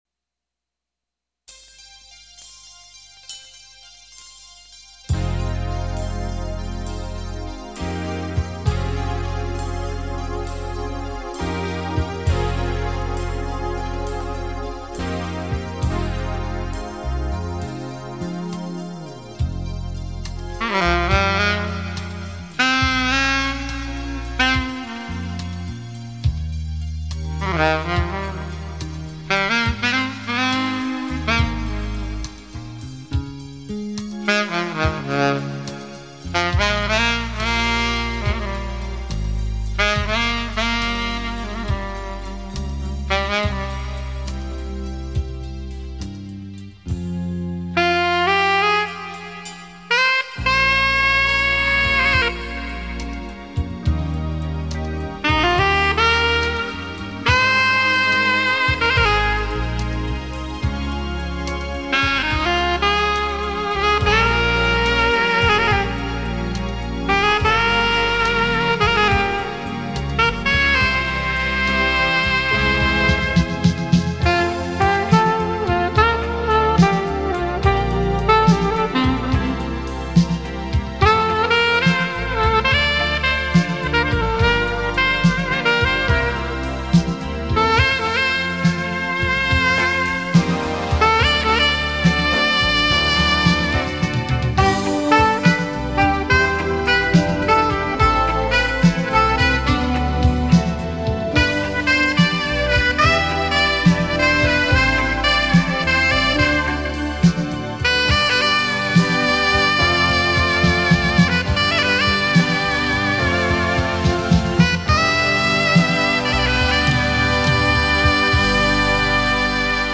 Genre: Instrumental.